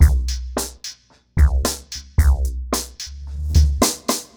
RemixedDrums_110BPM_10.wav